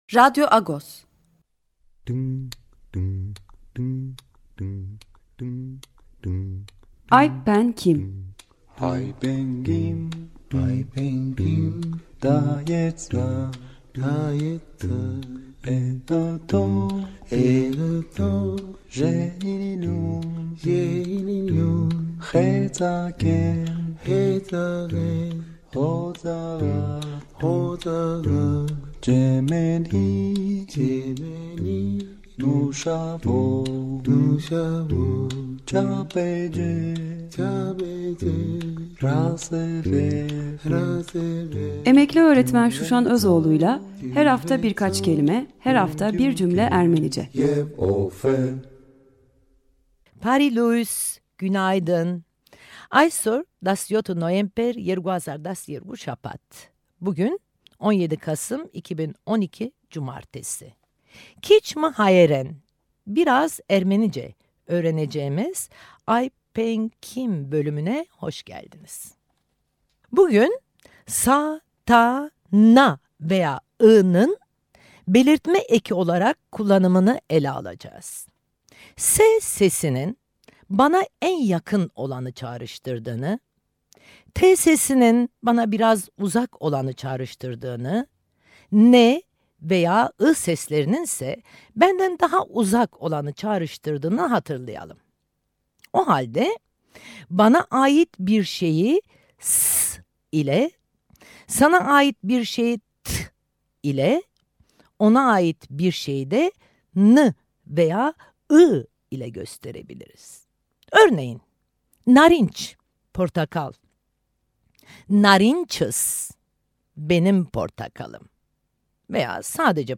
Her Cumartesi saat 10’da, Açık Radyo 94.9’da.